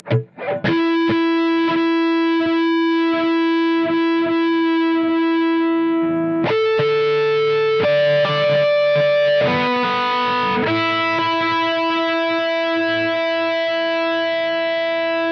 链条 " 链条缠绕
描述：非标准化的链条轻轻地嘎嘎作响，链条收紧或拉到最大。没有混响。
Tag: 金属 链条